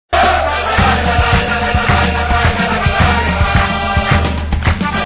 besiktas eroool Meme Sound Effect
Category: Sports Soundboard